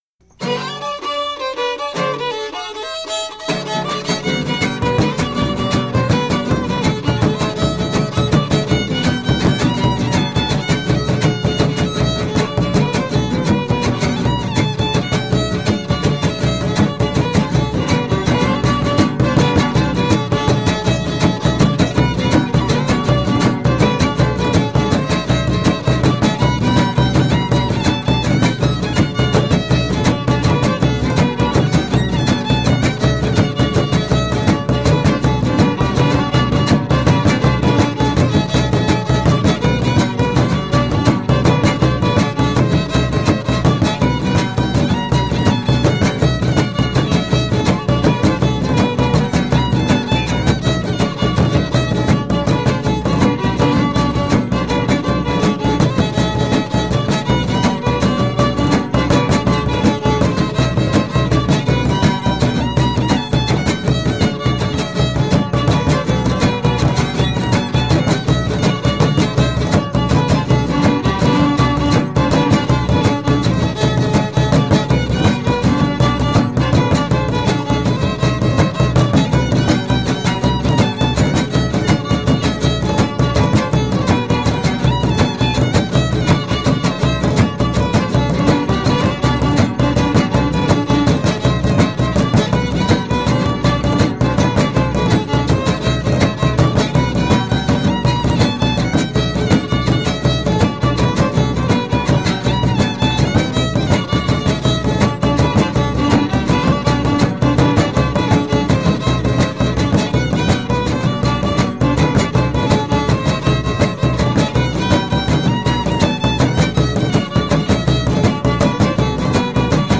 Keywords: arpa grande
folklor mexicano
Grabaciones de campo